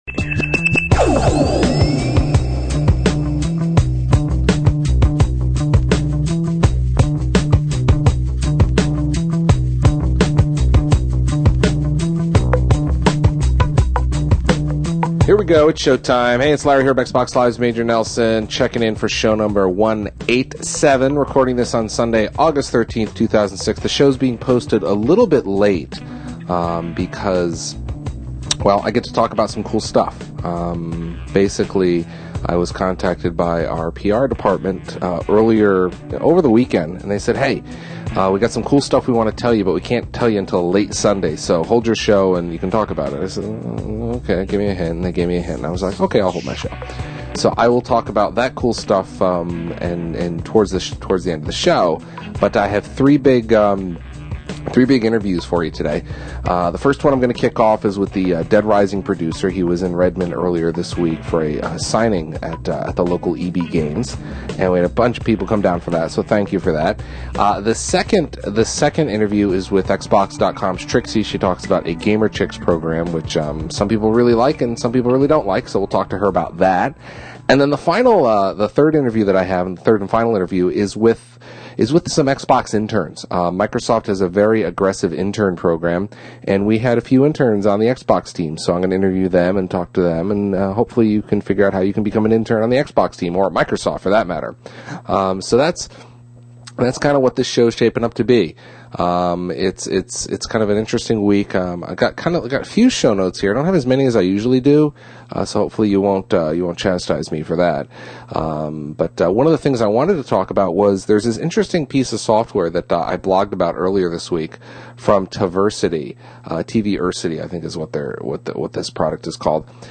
Show #187 The one with three interviews and XNA news - Xbox Wire